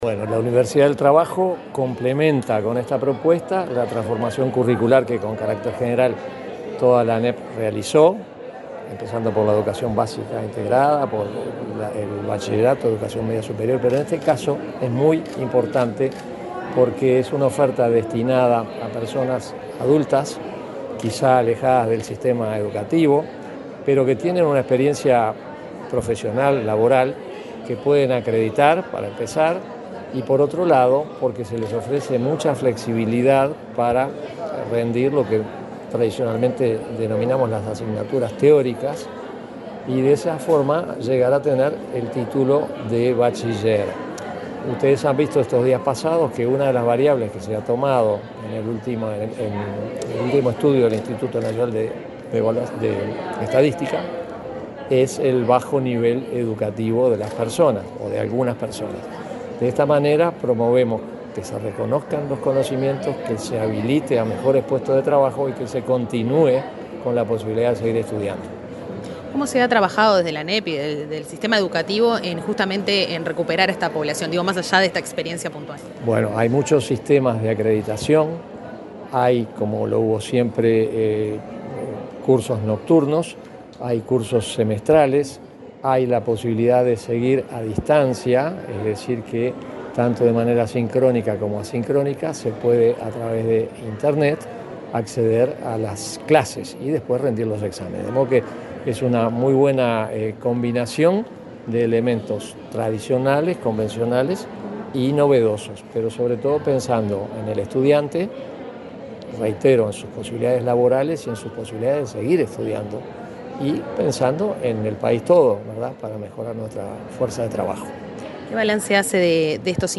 Entrevista al presidente de la ANEP, Juan Gabito Zóboli